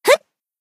BA_V_Wakamo_Swimsuit_Battle_Shout_1.ogg